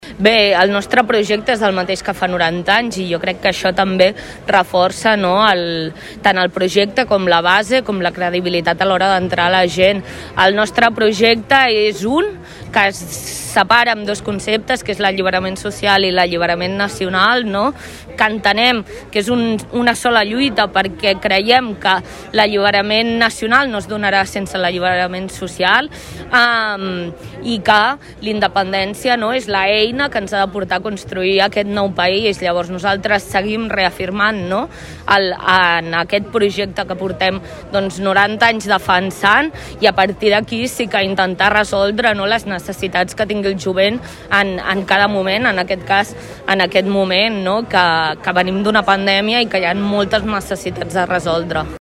Es commemora el 90è aniversari de Jovent Republicà en un acte a la plaça 1 d’octubre